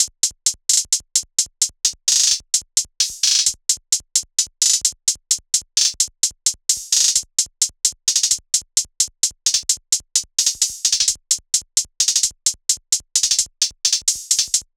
SOUTHSIDE_beat_loop_banger_hihat_130.wav